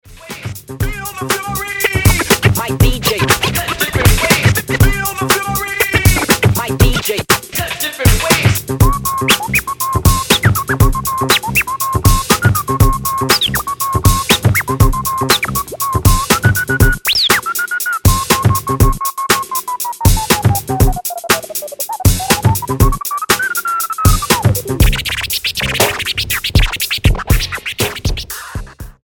turntablist
Style: Hip-Hop